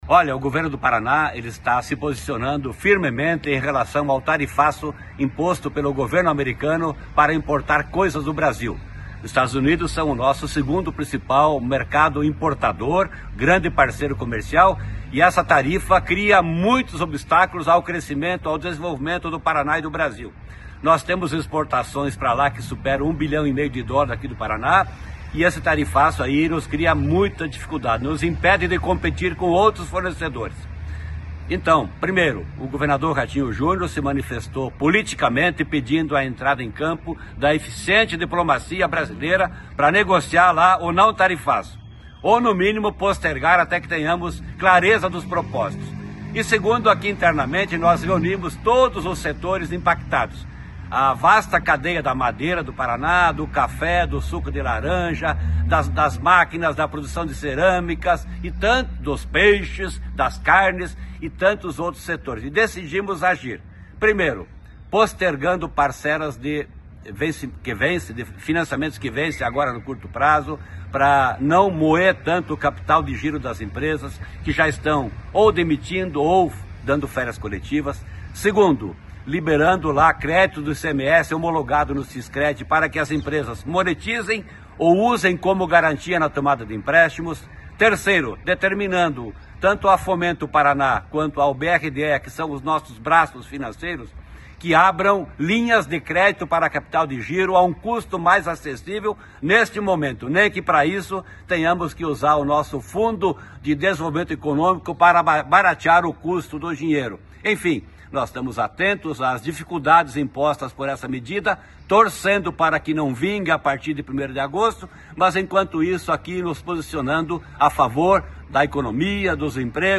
Sonora do secretário Estadual da Fazenda, Norberto Ortigara, sobre a ajuda do governo do Paraná a empresas eventualmente afetadas pelo tarifaço dos EUA